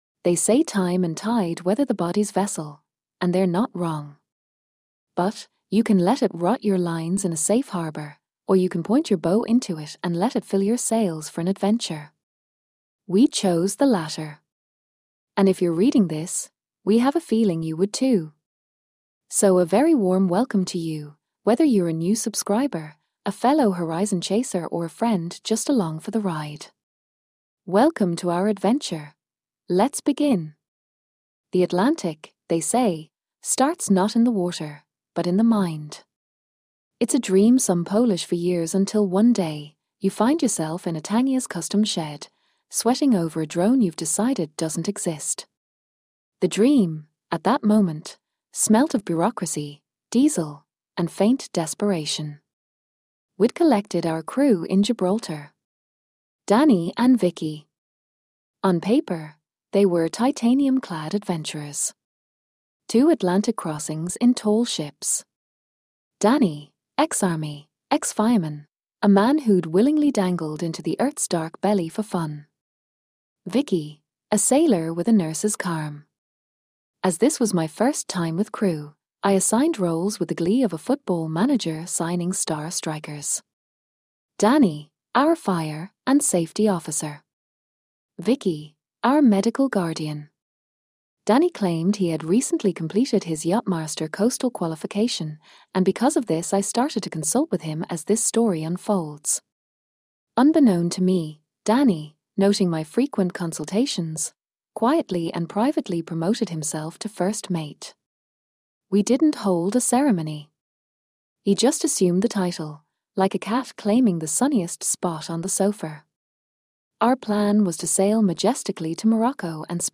Welcome to our adventure… but before we begin I am just trialing a voice reader as an option to the text... good or bad let me know what you think, Click Play me to listen or just carry on reading - let’s begin!